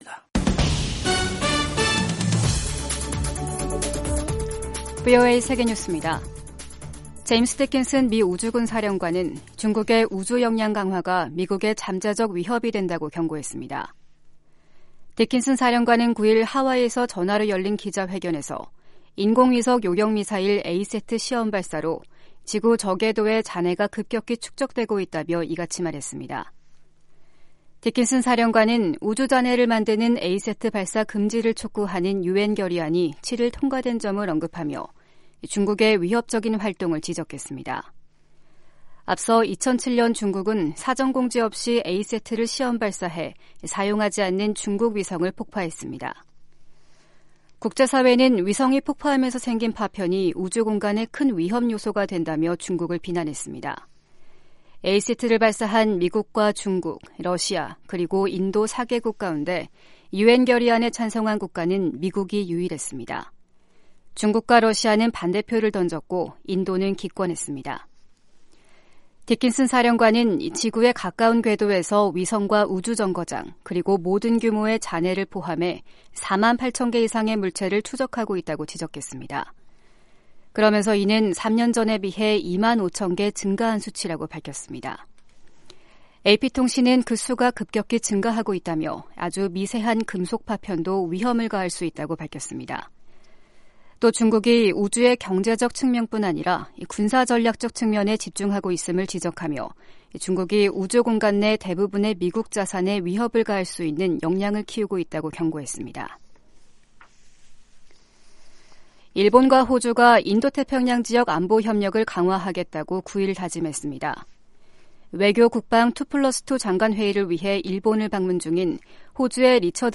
세계 뉴스와 함께 미국의 모든 것을 소개하는 '생방송 여기는 워싱턴입니다', 2022년 12월 10일 아침 방송입니다. 이란 사법부가 반정부 시위 참가자에 대한 첫 사형을 집행했습니다. 미 연방 상원에 이어 하원에서도 동성 간의 결혼을 보호하는 ‘결혼존중법안’이 통과됐습니다.